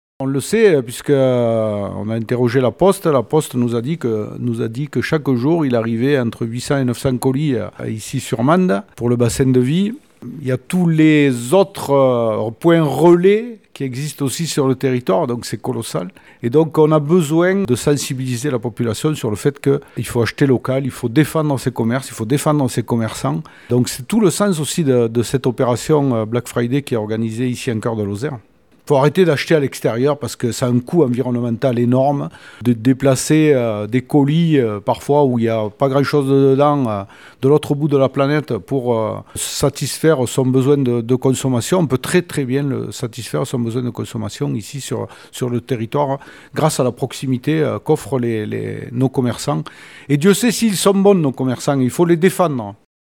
Laurent Suau, le président de la communauté de communes Cœur de Lozère.